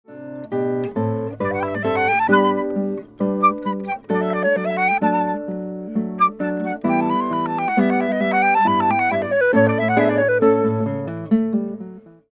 • Beyer MCE93 cardioid condenser microphones
flute/guitar
duo   Anechoic
Exactly as recorded.
I am listening to an anechoic stereo signal via headphones: The sound is very clear but completely inside my head.